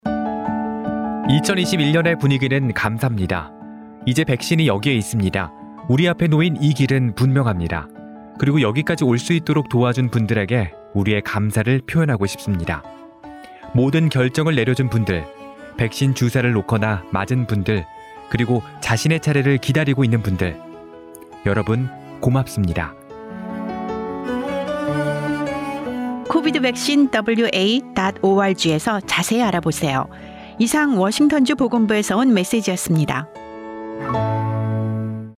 Gratitude Korean Radio Help overcome vaccine hesitancy Together We Will
DOH_COVID19_TogetherWeWill_Gratitude_Radio_KOREAN.mp3